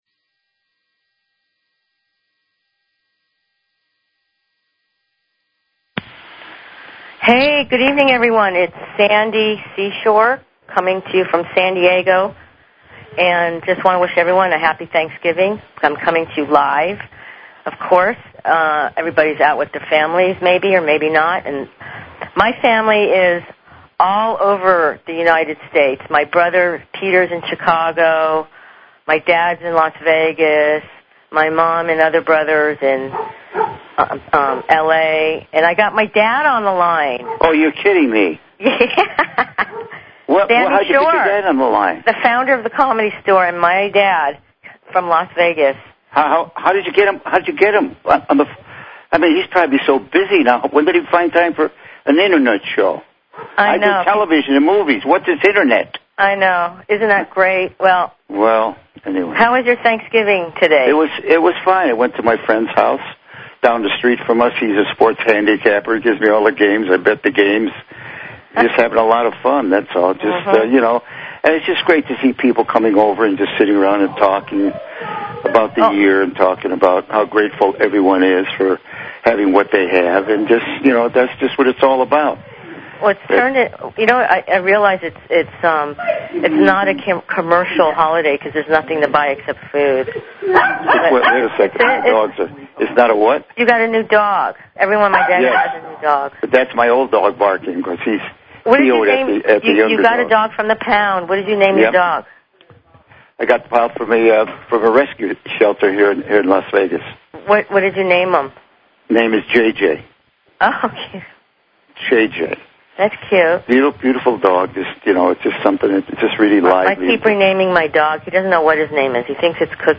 Talk Show Episode, Audio Podcast, Happy_Hour_Radio and Courtesy of BBS Radio on , show guests , about , categorized as
Live show...